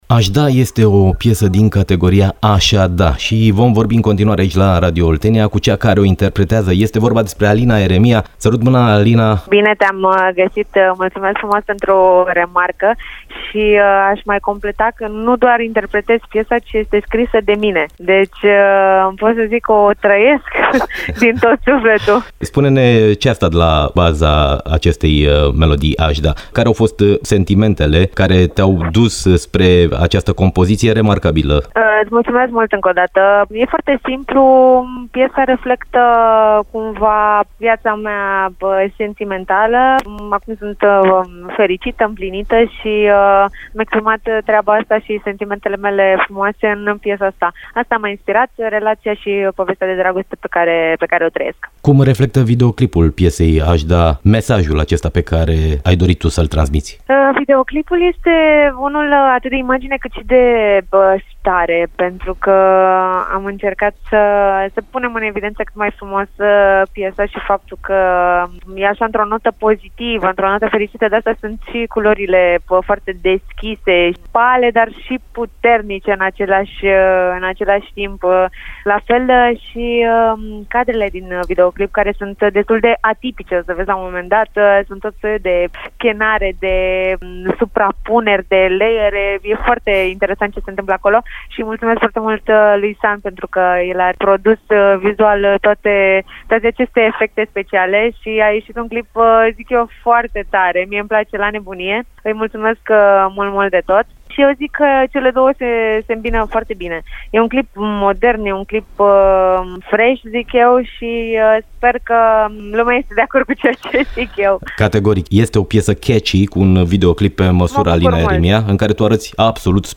Artista a vorbit despre hitul "Aș da" la Radio Oltenia.
Interviu cu Alina Eremia